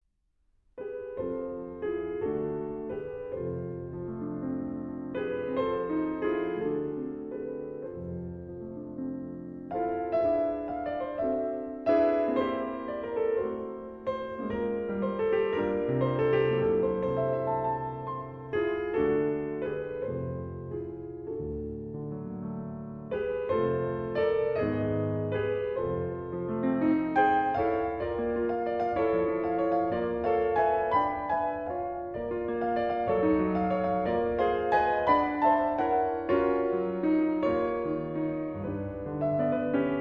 Intermezzi, Op. 141 - No. 2 In G Minor: Allegro con fuoco